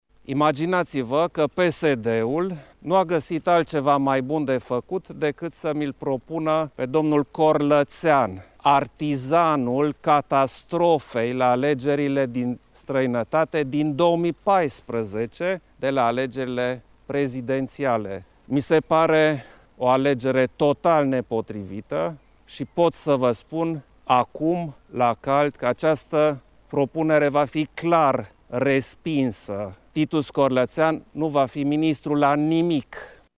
Președintele a subliniat că îl consideră pe Corlățean „artizanul dezastrului de la alegerile din 2014”: